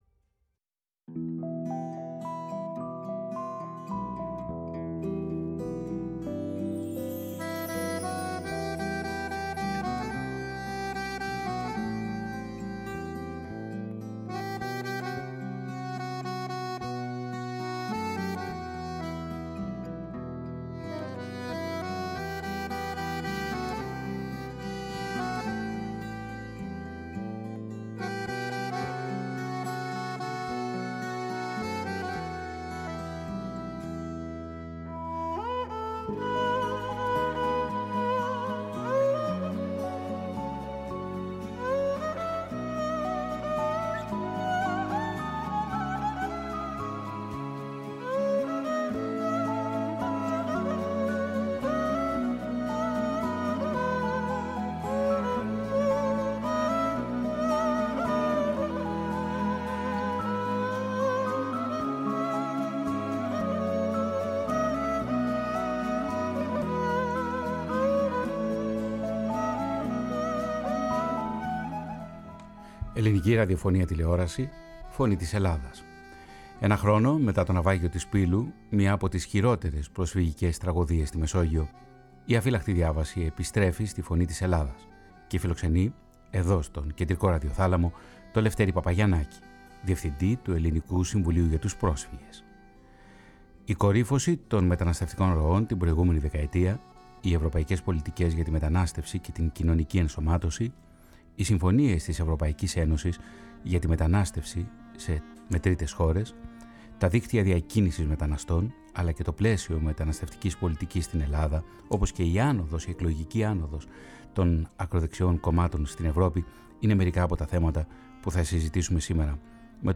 Έναν χρόνο μετά το ναυάγιο της Πύλου, μία από τις χειρότερες προσφυγικές τραγωδίες στη Μεσόγειο, η “Αφύλαχτη Διάβαση” φιλοξενεί στον ραδιοθάλαμο της Φωνής της Ελλάδας τον Λευτέρη Παπαγιαννάκη, διευθυντή του Ελληνικού Συμβουλίου για τους Πρόσφυγες.